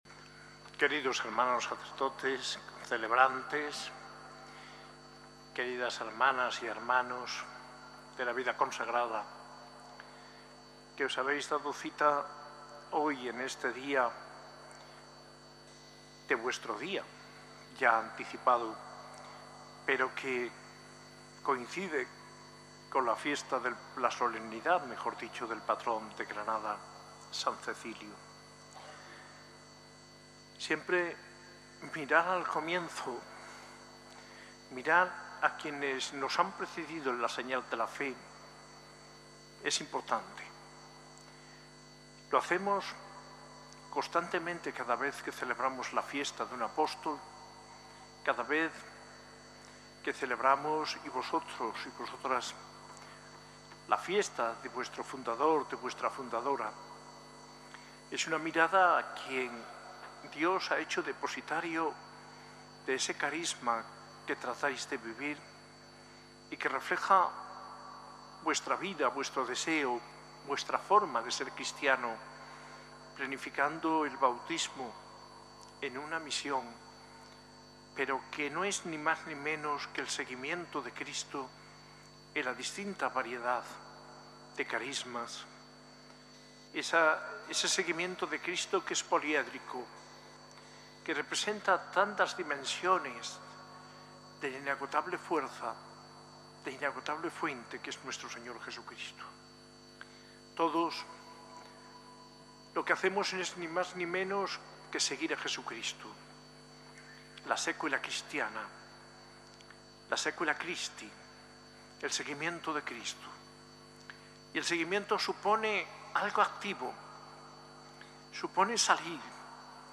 Homilía de D. José María Gil Tamayo, arzobispo de Granada, en la Eucaristía en la Jornada por la Vida Consagrada, el 1 de febrero de 2025, en la S. A. I. Catedral de Granada.